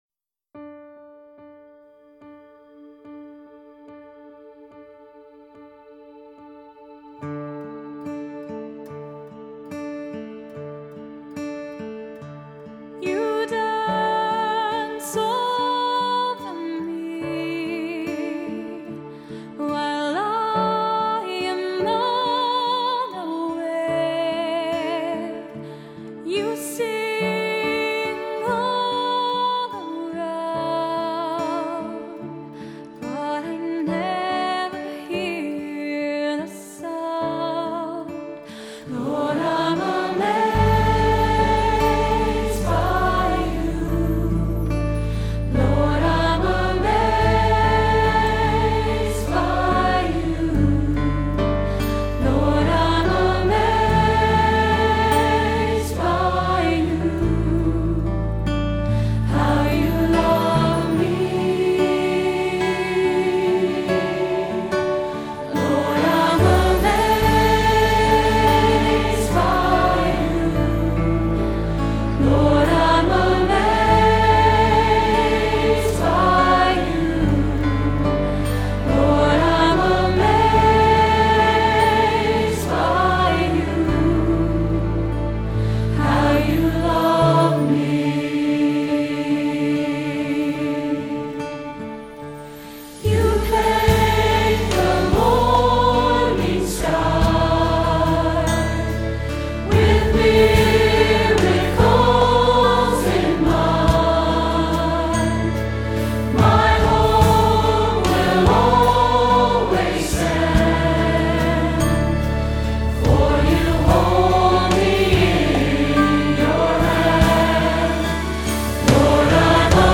This Sunday’s Anthem
Remember to watch me for the swells and be diligent about the syncopation. This song has a lot of repeats, but I will remind you when we are going to the second ending!